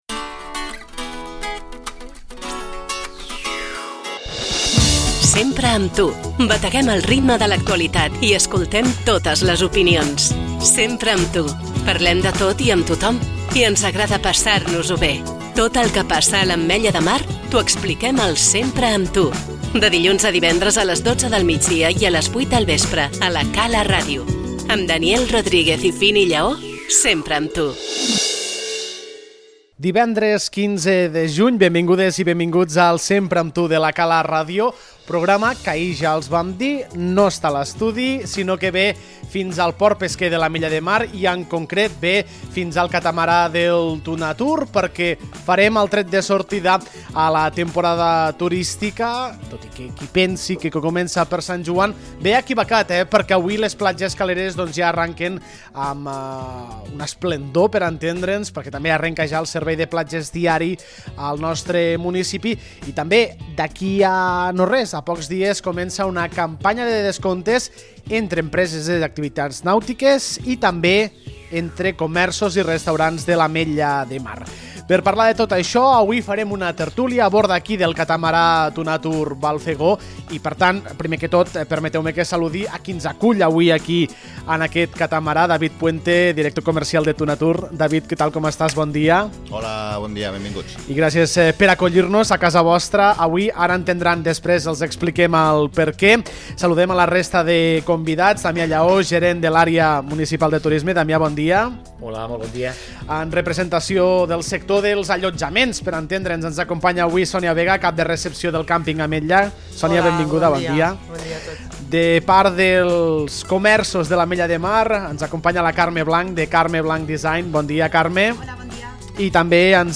Ens embarquem a bord del catamarà Tuna Tour per salpar cap a la temporada turística de l’Ametlla de Mar. Quines previsions tenen els allotjaments? Quines campanyes impulsen comerciants, empreses nàutiques i restauradors?